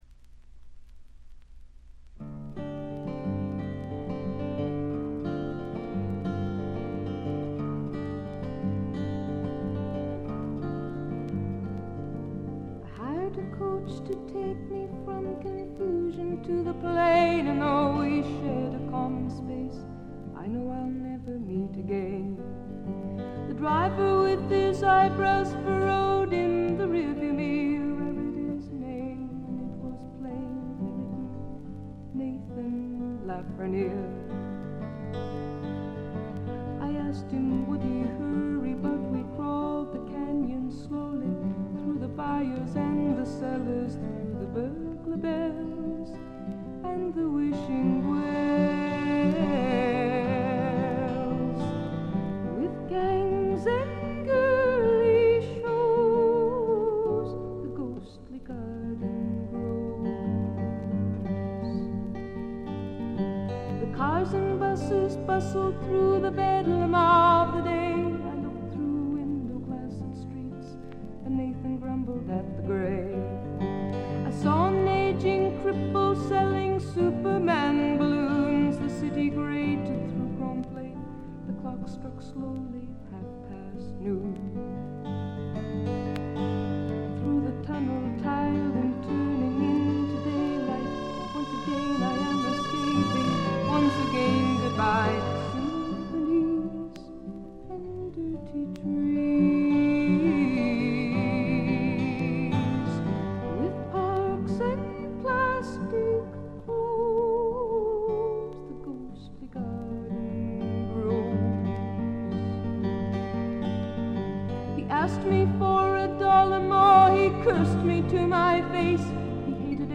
全体にバックグラウンドノイズ。
至上の美しさをたたえたサイケ・フォーク、アシッド・フォークの超絶名盤という見方もできます。
試聴曲は現品からの取り込み音源です。
guitar, piano, vocals